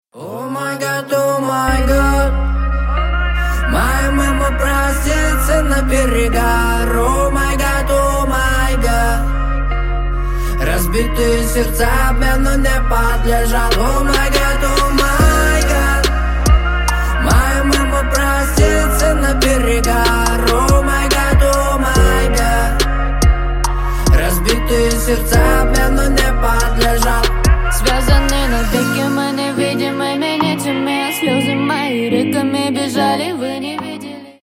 Грустные Рингтоны
Рэп Хип-Хоп